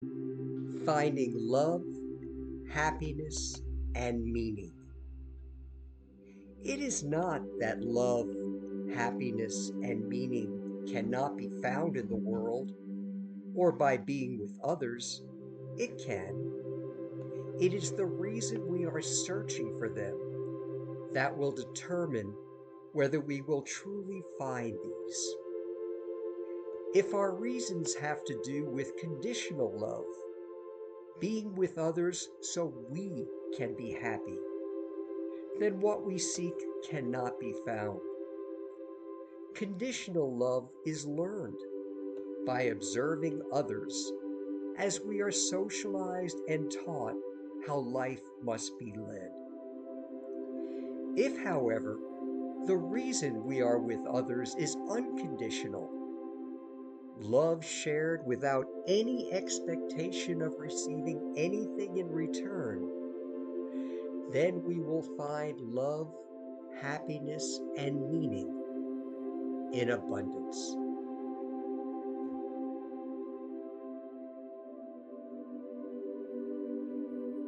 An audio Spiritual Reflection